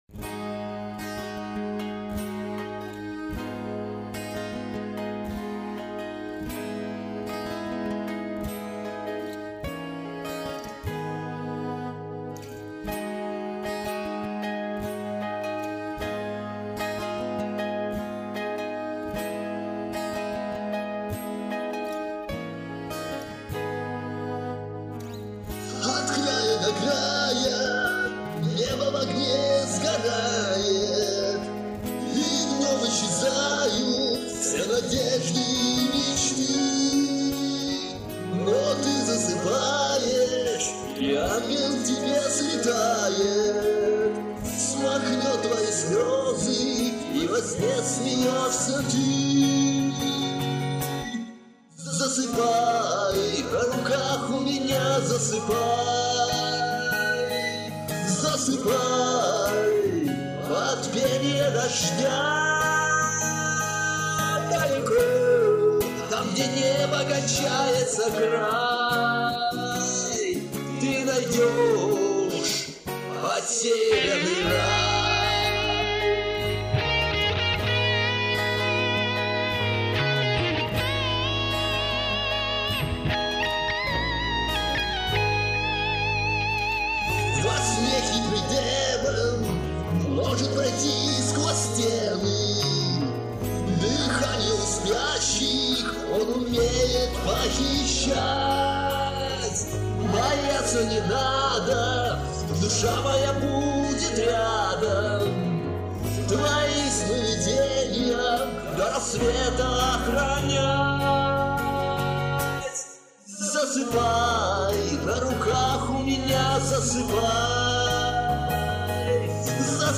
А рок я люблю, вот и стараюсь в нем.